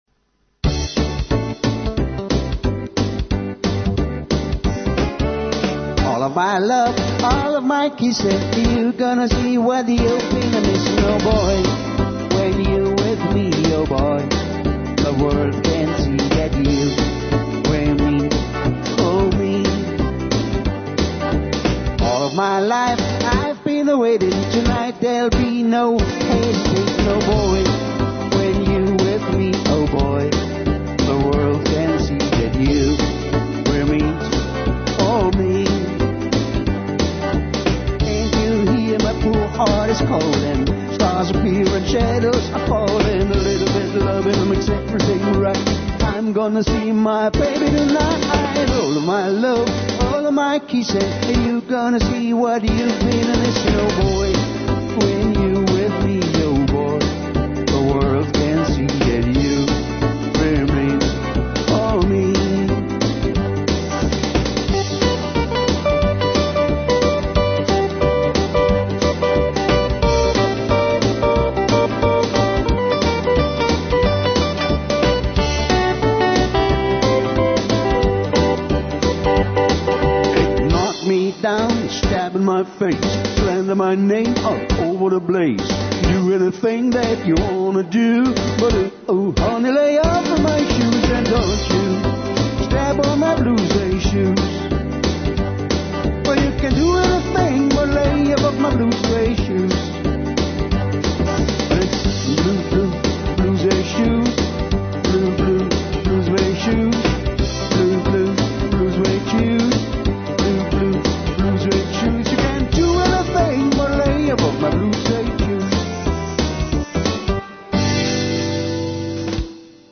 Medley Rockabilly